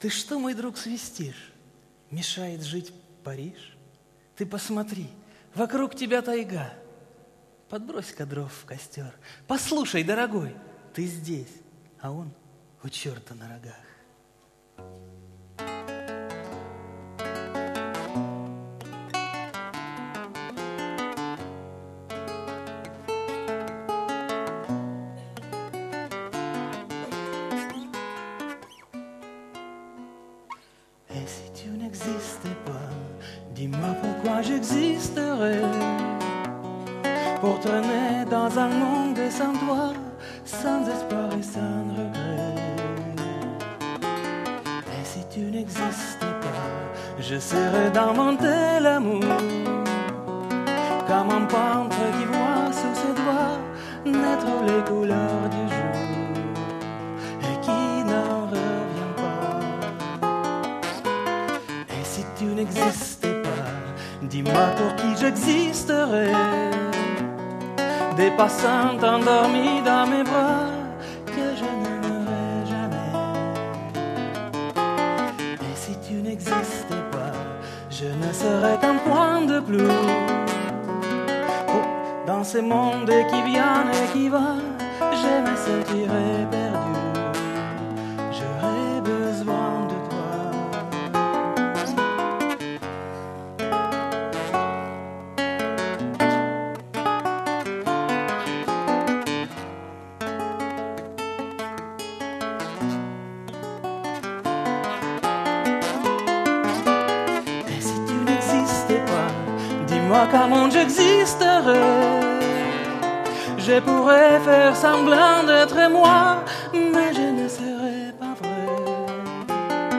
Концерт 06.11.2008 года.